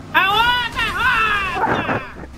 Meme Sound Effect for Soundboard